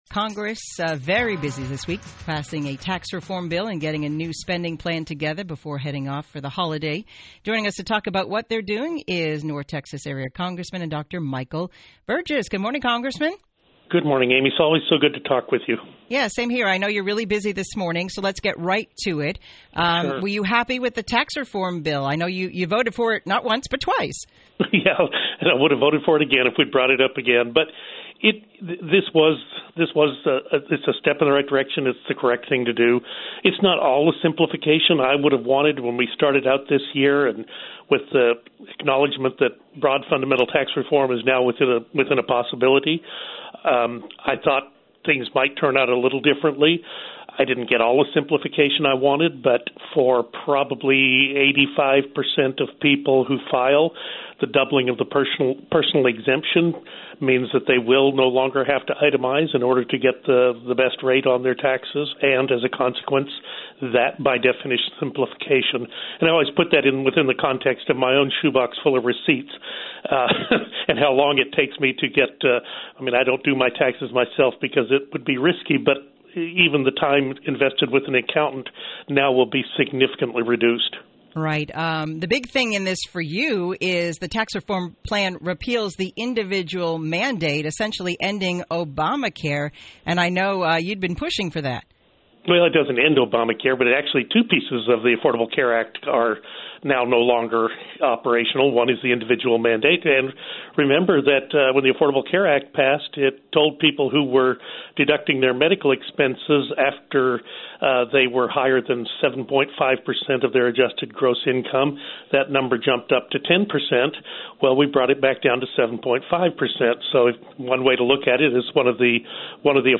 Thursday morning, Dr. Burgess joined The Morning Drive. He says he’s quite proud of lawmakers for coming together to pass the first major overhaul to taxes in more than 30 years.